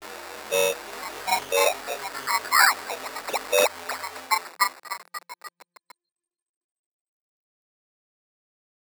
glitch sound I've been cooking up
glitch1.wav